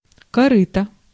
Ääntäminen
IPA: [oʒ]